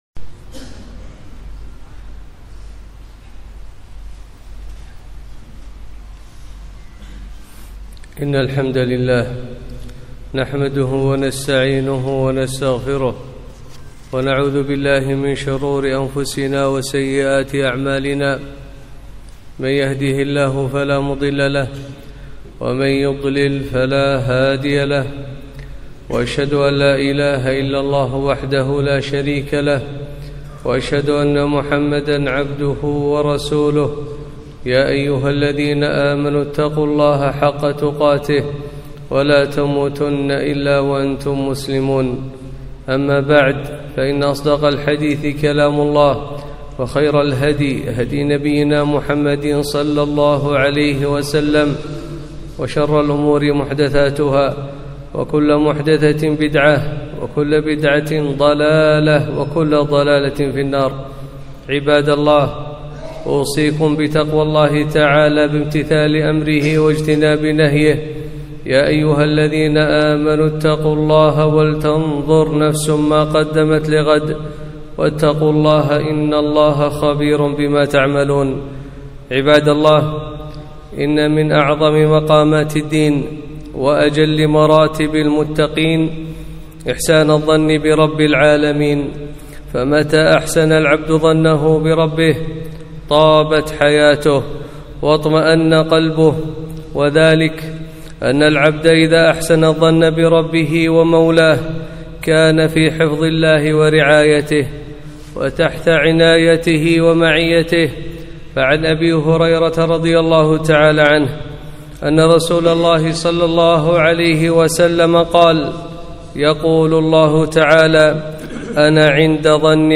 خطبة - حسن الظن بالله